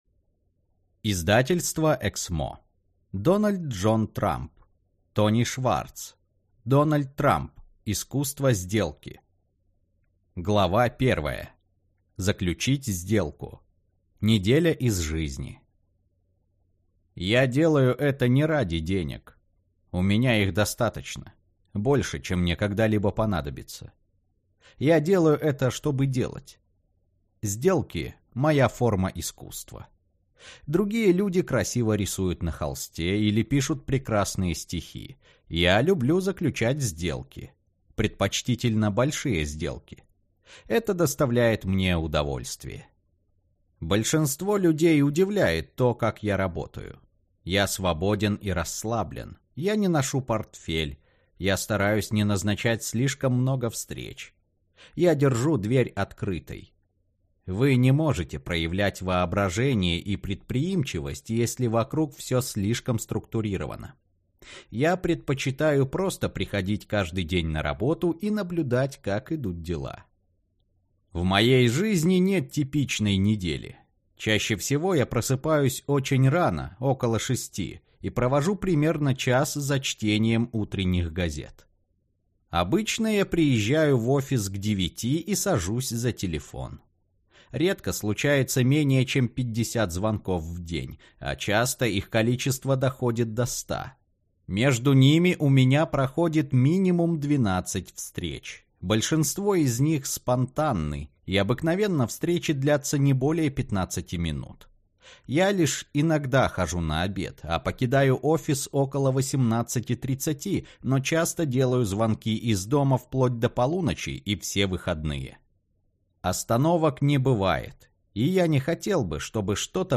Аудиокнига Дональд Трамп. Искусство сделки | Библиотека аудиокниг